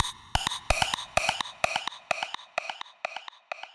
周围环境80
Tag: 80 bpm Ambient Loops Pad Loops 2.02 MB wav Key : A